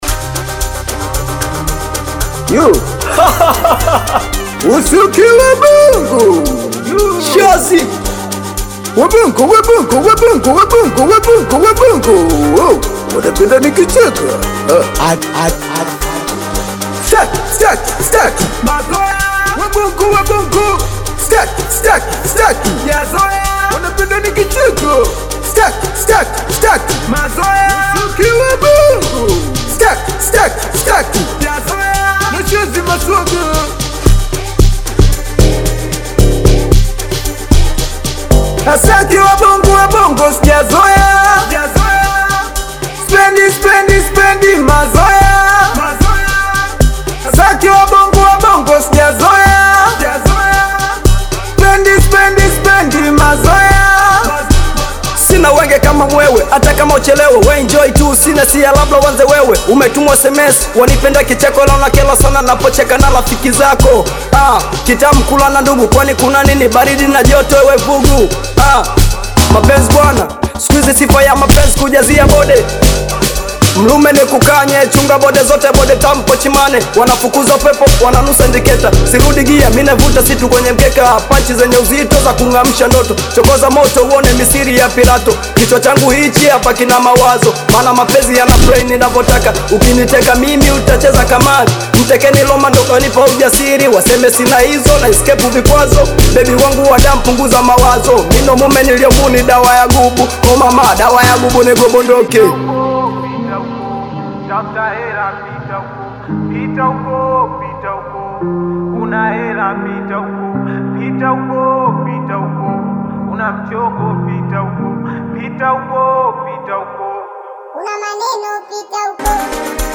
Audio Bongo flava Latest